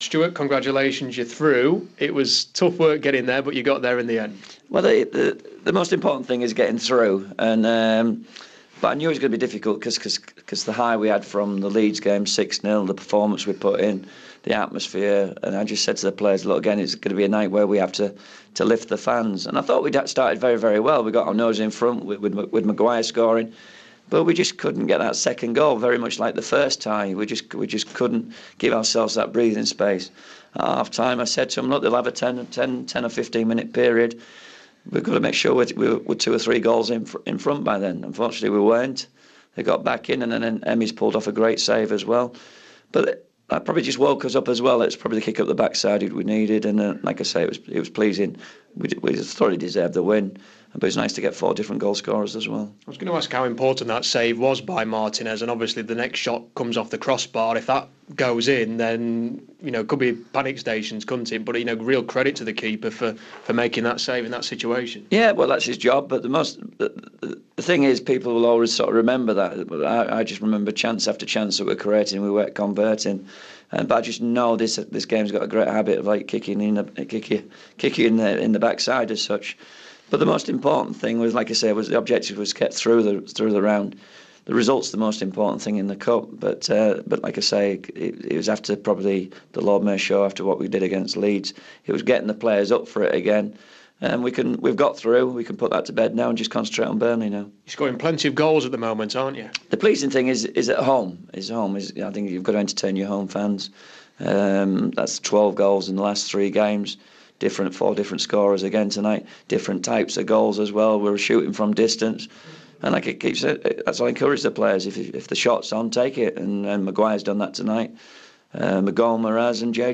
Interview: Stuart Gray on tonight's 4-1 win over Macclesfield